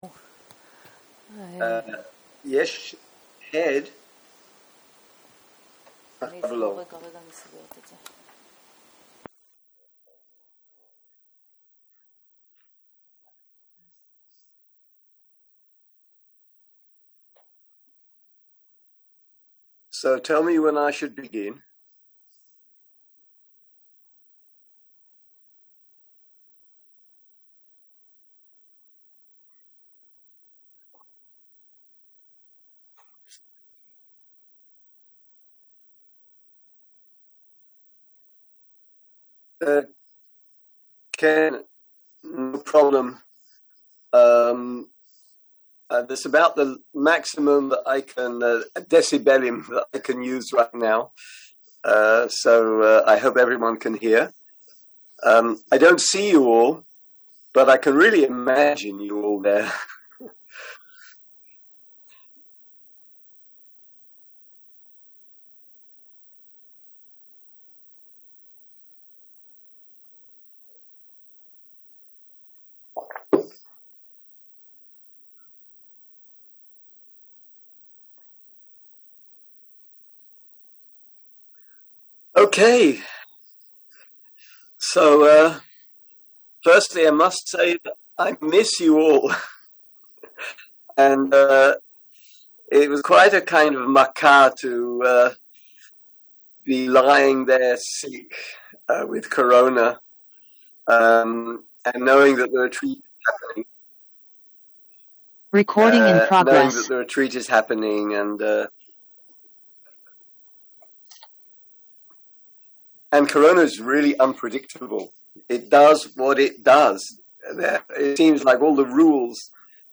שיחות דהרמה